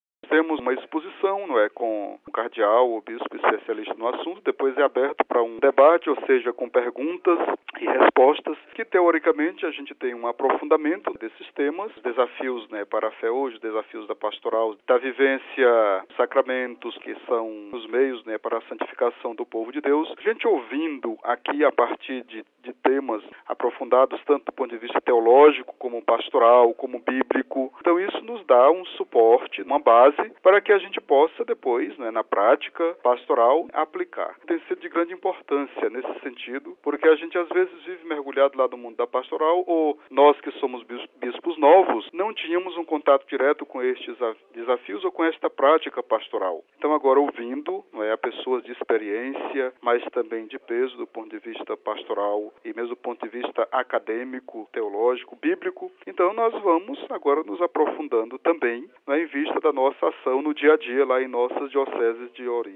Para Dom Juarez Sousa da Silva, bispo de Oeiras, no Piauí, o encontro é uma oportunidade de aprofundamento de temas relacionados às atividades dos bispos. Dom Juarez contou à Rádio Vaticano de que forma o encontro é organizado e qual a sua importância.